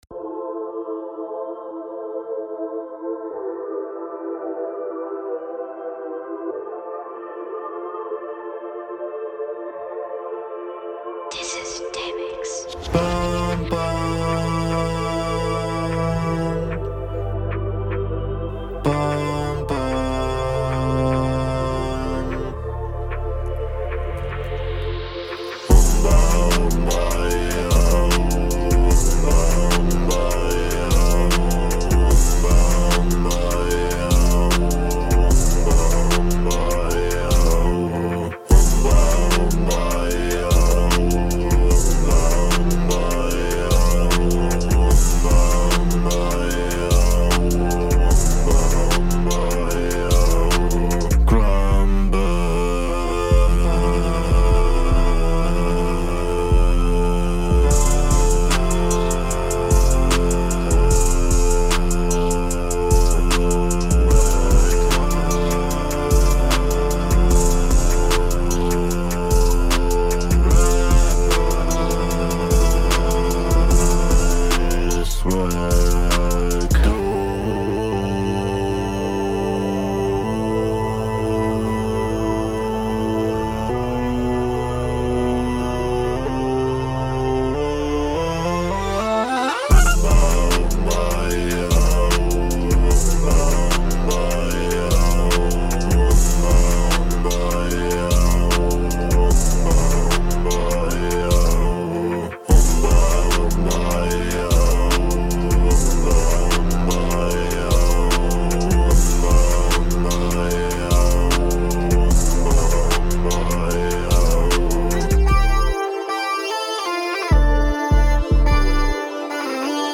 Hinrunde